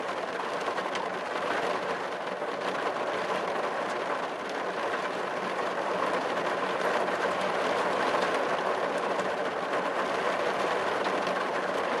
rain_podval.ogg